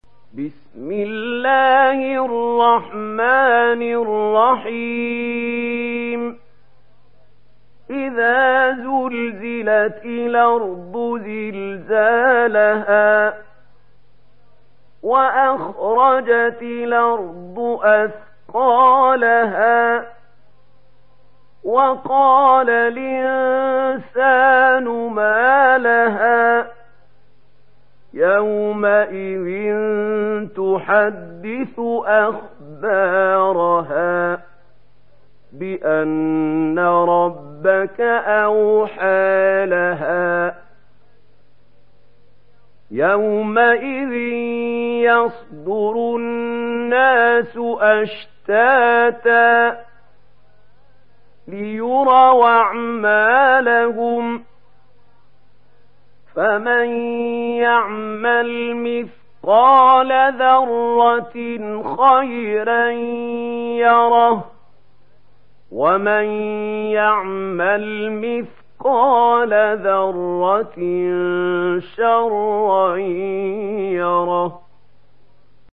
Surah Az-Zalzalah MP3 in the Voice of Mahmoud Khalil Al-Hussary in Warsh Narration
Murattal Warsh An Nafi